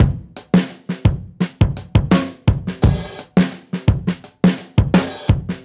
Index of /musicradar/dusty-funk-samples/Beats/85bpm
DF_BeatC_85-02.wav